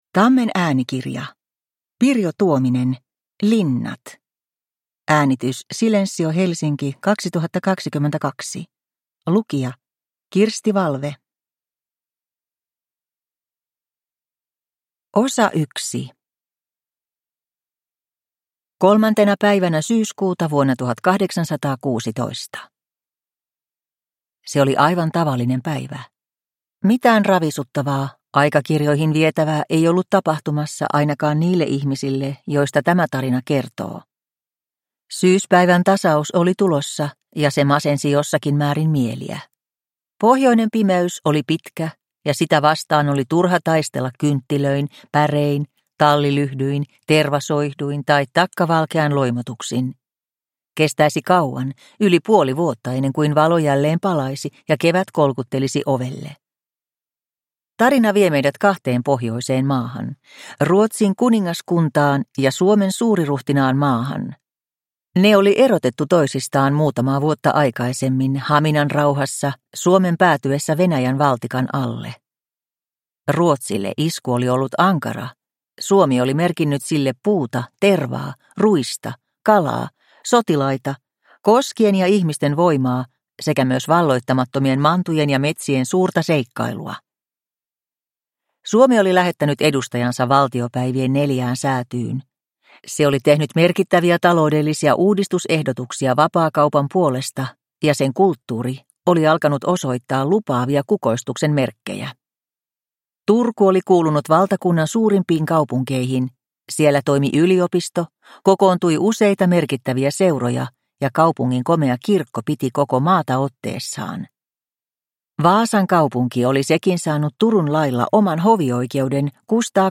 Linnat – Ljudbok – Laddas ner